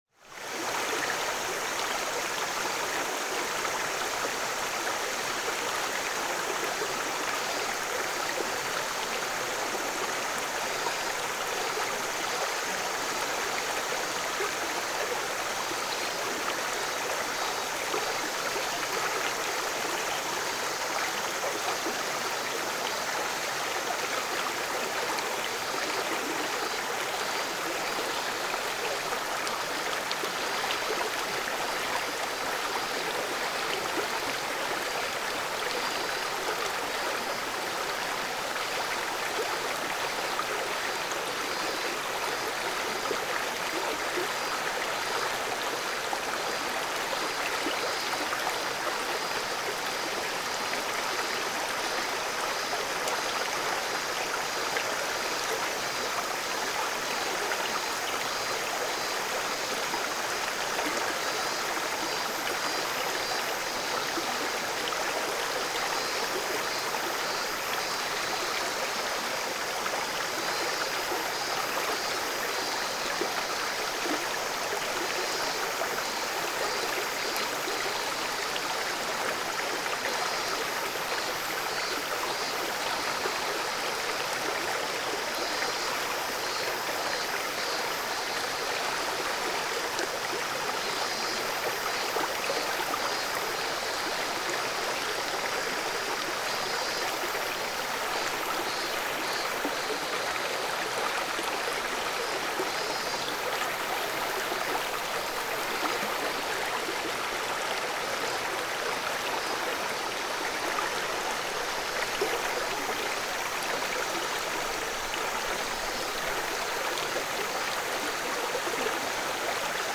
小川のせせらぎ02 - 音アリー
river_stream_02.mp3